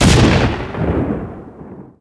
explo3.wav